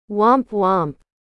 Sabe aquele som de trombone ou trompa que acompanha cenas onde alguém fracassa ou se dá mal de forma engraçada?
Esse som de desapontamento começou a ser reproduzido como womp womp, ganhando força nas redes sociais e em memes pra indicar situações de expectativa quebrada ou algo que deu errado, mas que ninguém levou muito a sério.
Womp-Womp.mp3